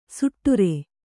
♪ suṭṭure